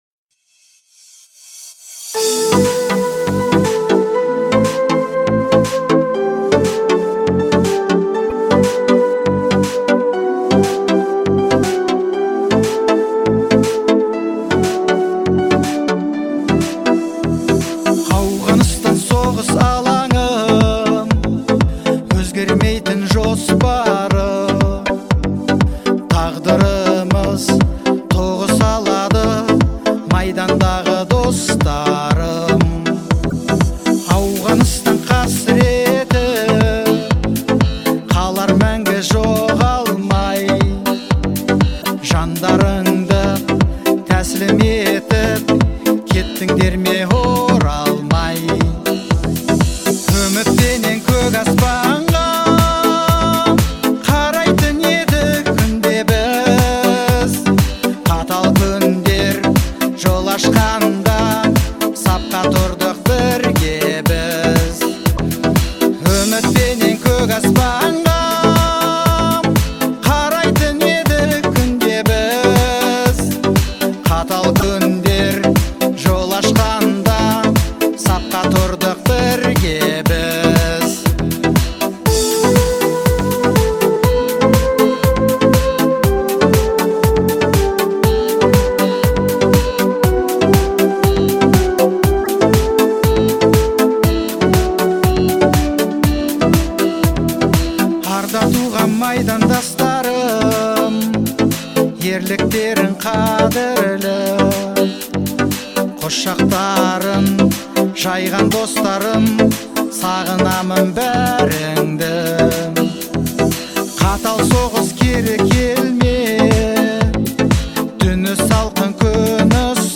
Звучание песни отличается выразительным вокалом